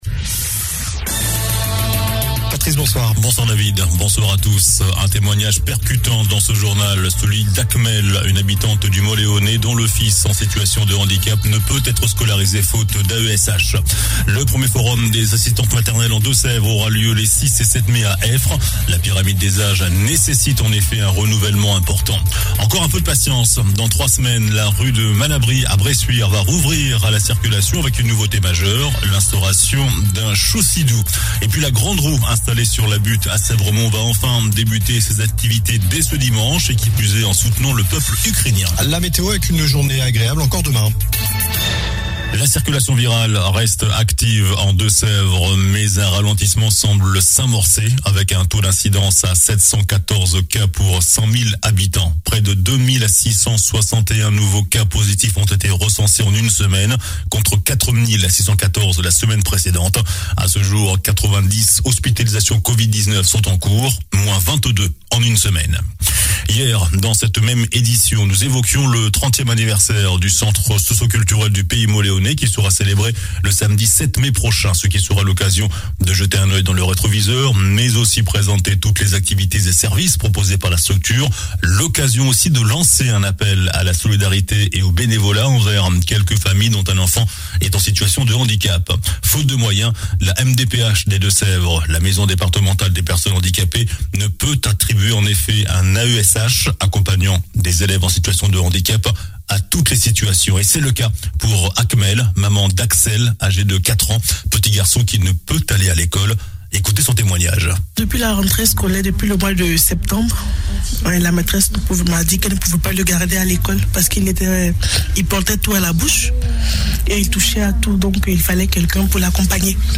JOURNAL DU MARDI 26 AVRIL ( SOIR )